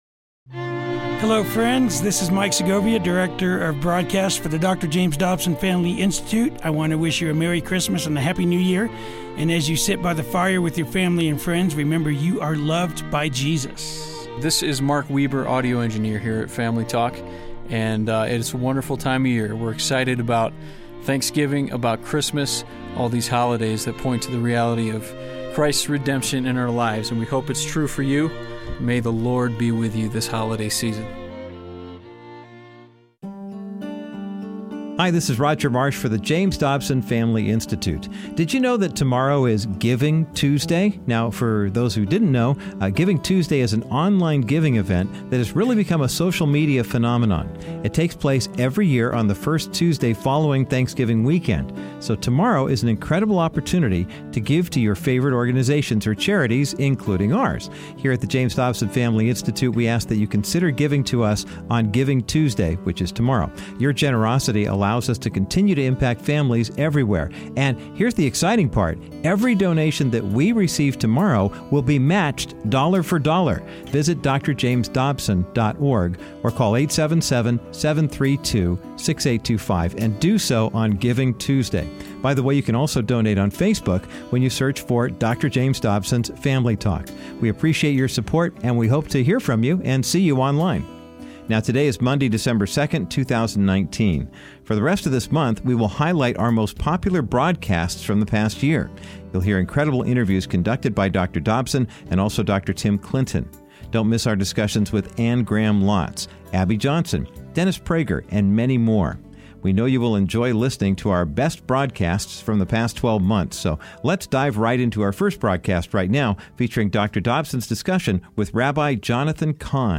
Today you will hear Dr. Dobsons interview with New York Times best-selling author, Rabbi Jonathan Cahn. Rabbi Cahn shares his journey to faith in Jesus, then discusses his new book, The Oracle and the mysteries behind the years of Jubilee.